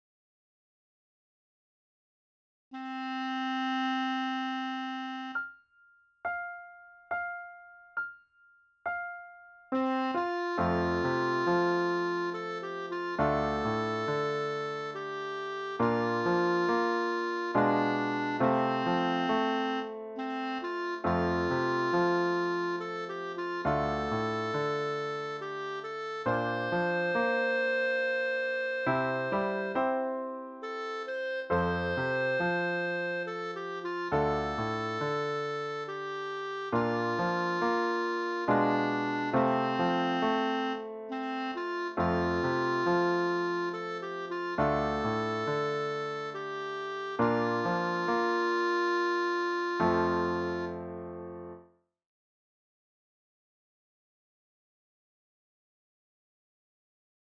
Ici, on pourra acceder à une version accompagnée des mélodies et chansons apprises lors de nos cours.
Une très belle mélodie pour travailler le “triolet”